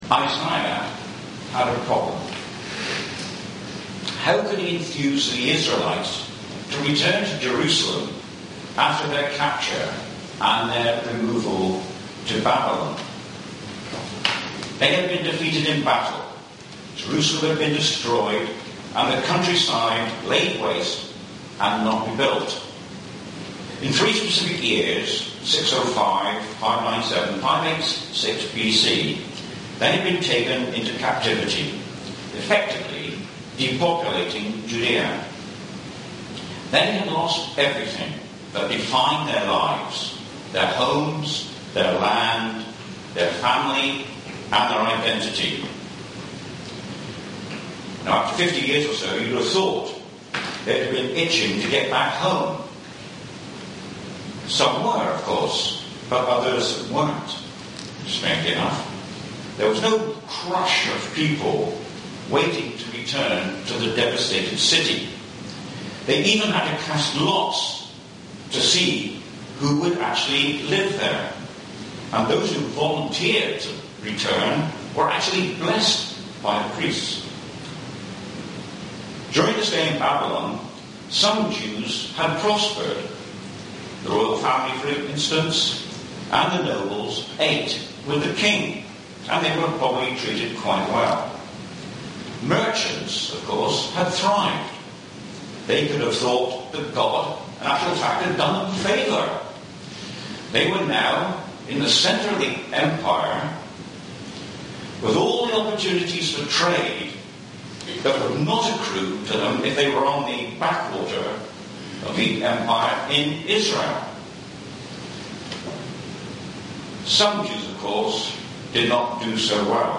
Sermon-4-Feb-2018.mp3